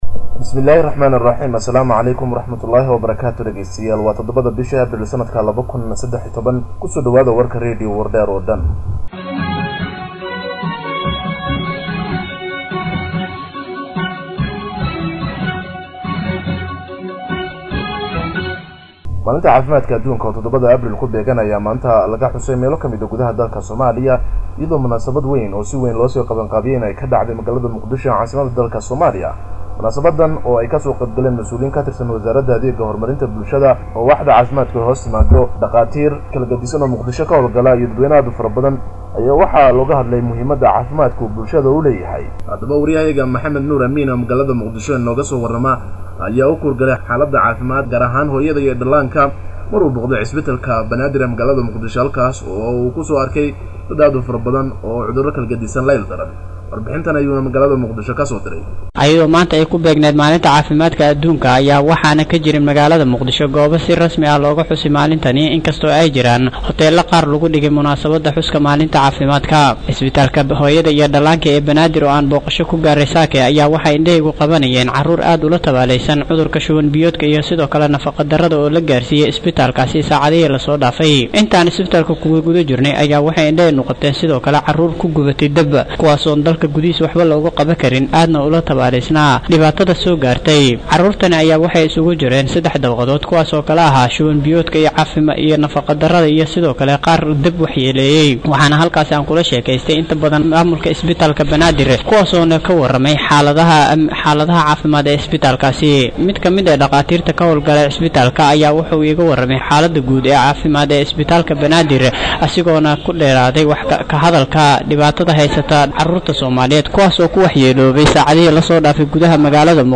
Audio Daily News, News, Radio Wardheer, Warar